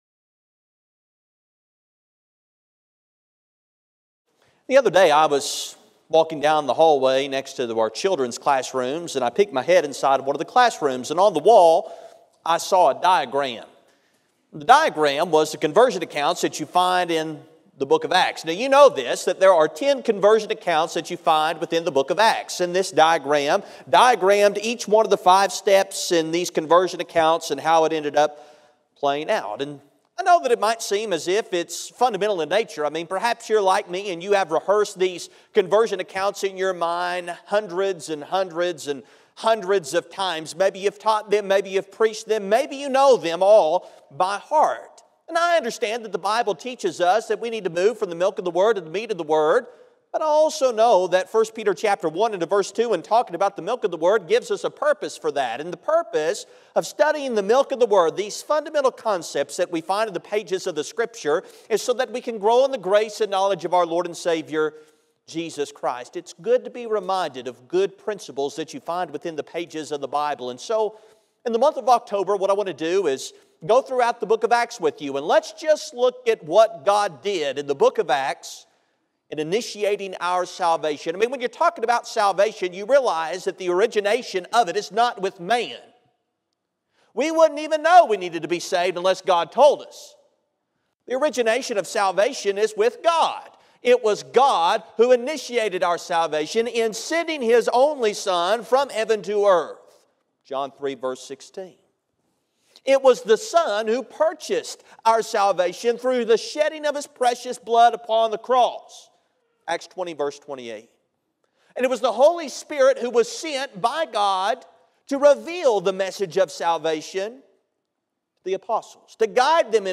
The sermon is from our live stream on 10/6/24